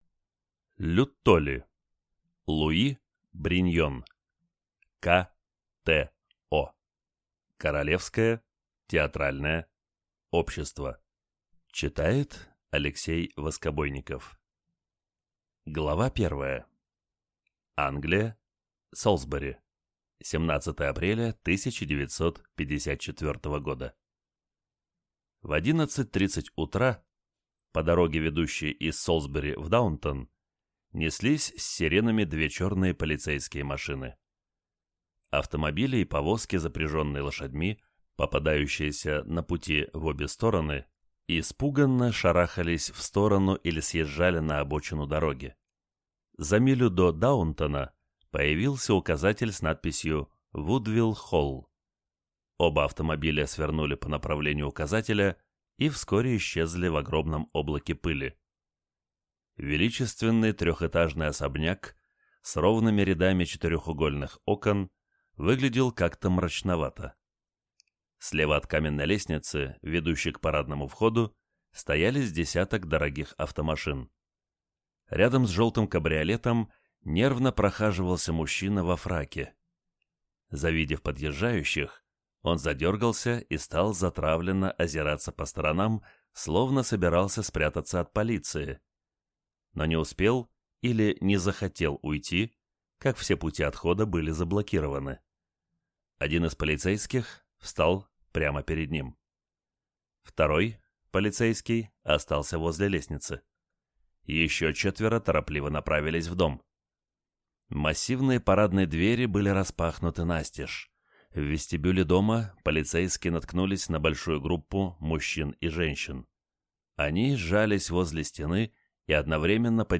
Aудиокнига К.Т.О. Автор Люттоли
Прослушать и бесплатно скачать фрагмент аудиокниги